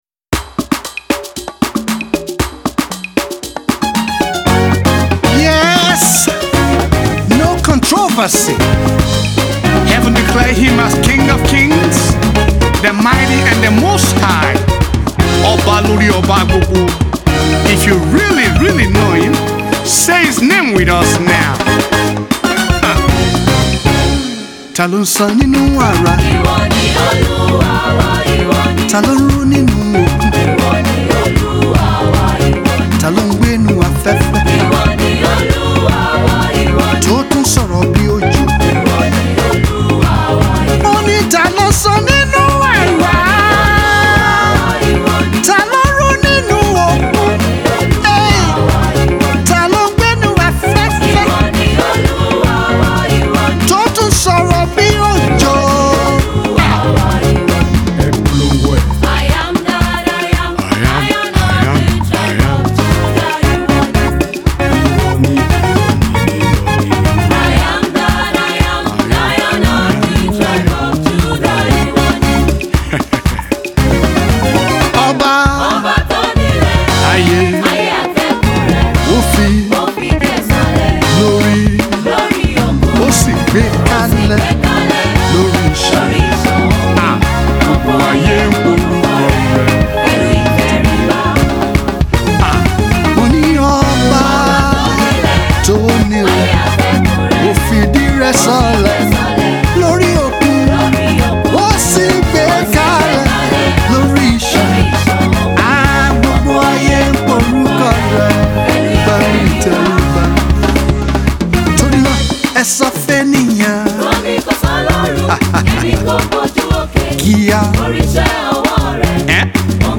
Nigerian Indigenous Gospel singer
powerful praise medley